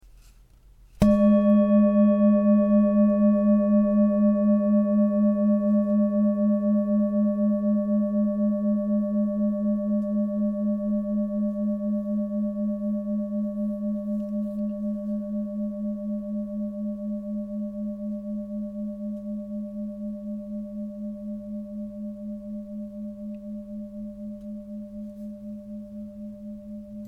Tibetische Klangschale - SEDNA + VESTA
Grundton: 210,14 Hz
1. Oberton: 602,89 Hz
PLANETENTON SEDNA TON GIS